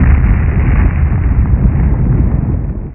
fire-01.wav